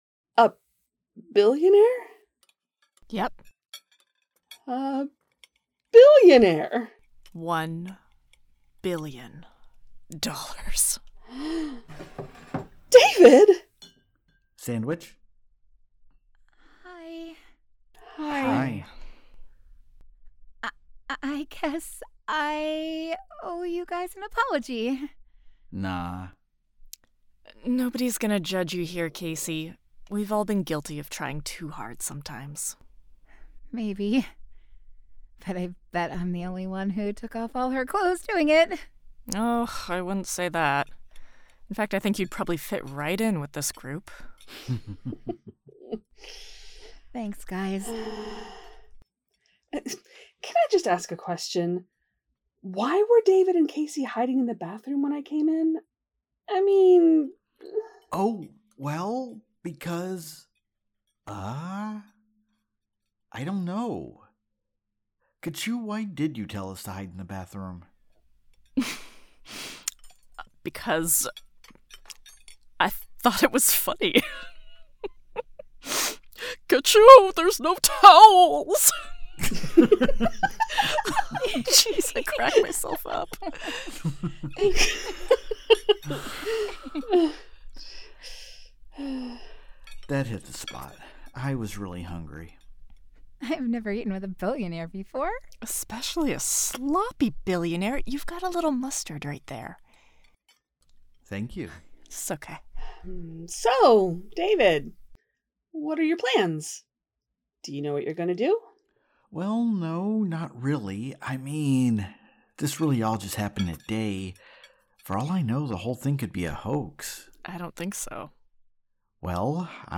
The Ocadecagonagon Theater Group
strangers-in-paradise-the-audio-drama-book-7-episode-16